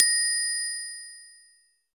Glocken.m4a